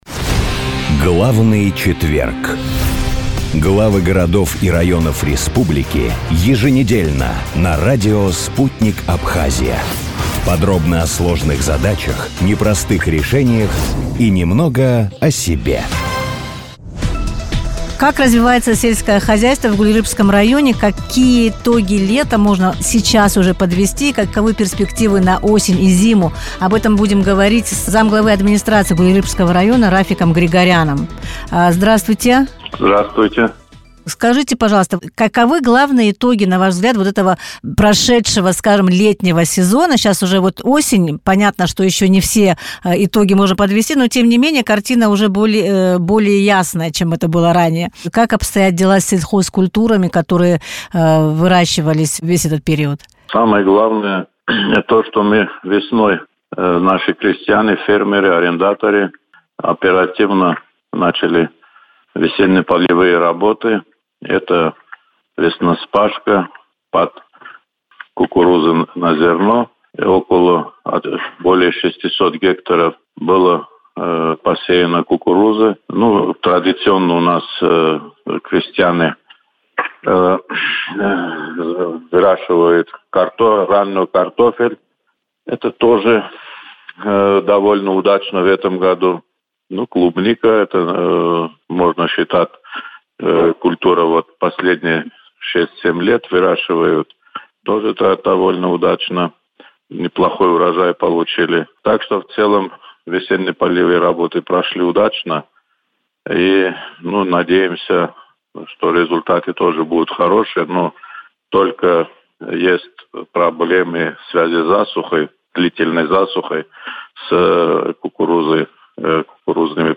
Как развивается сельское хозяйство в Гулрыпшском районе, с какими показателями закончили лето, каковы перспективы на осень и зиму, в эфире радио Sputnik рассказал замглавы администрации района Рафик Григорян.